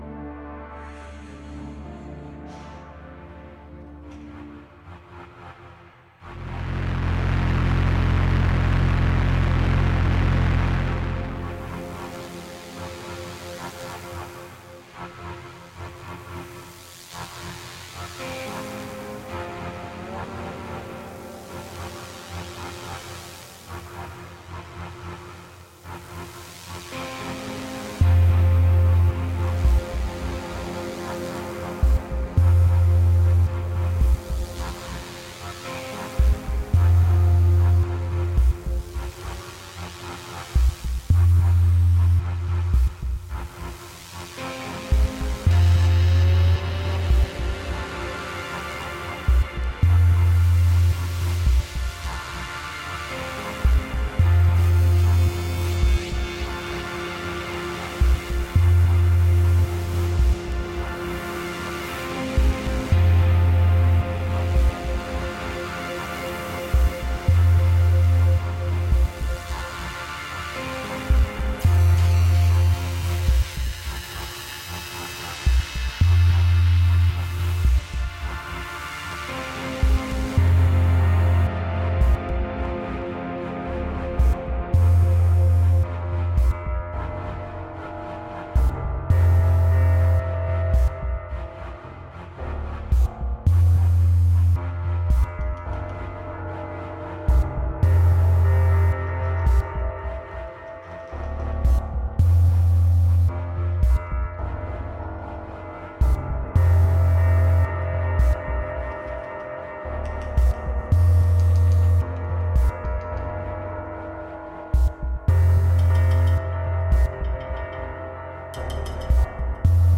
Tagged as: Electronica, Experimental